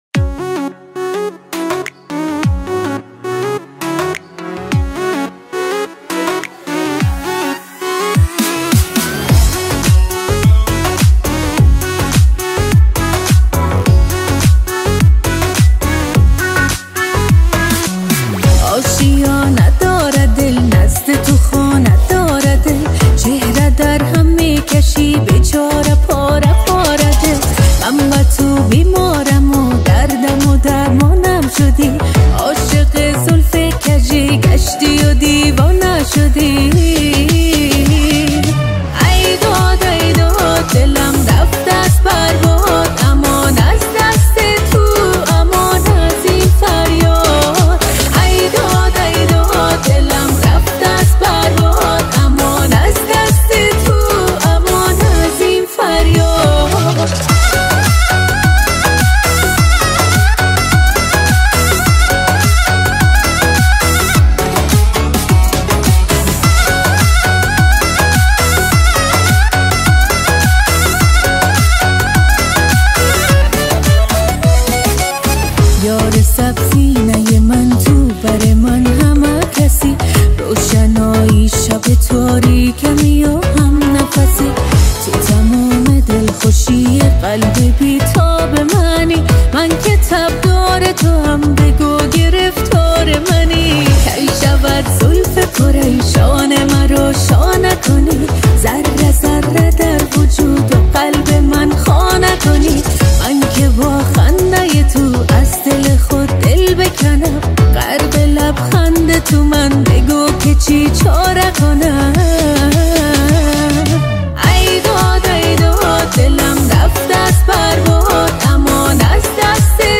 Категория: Иранские